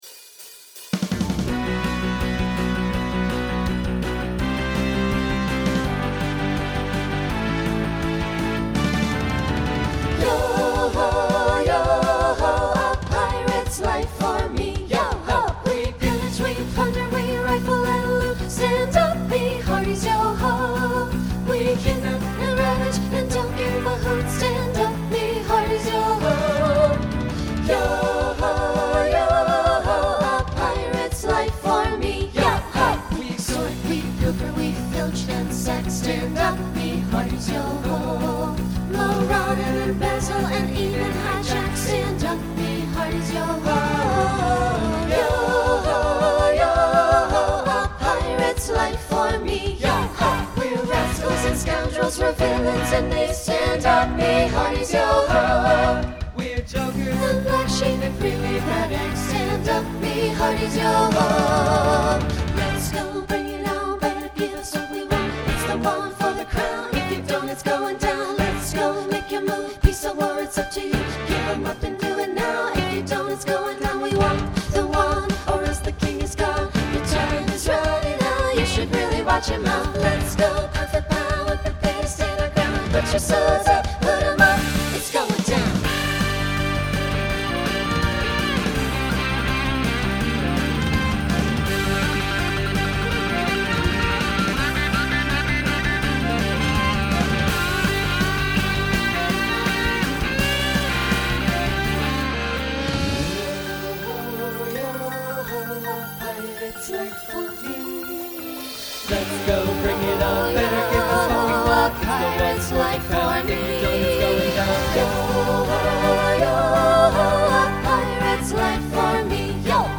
New SSA voicing for 2022.
Genre Broadway/Film , Rock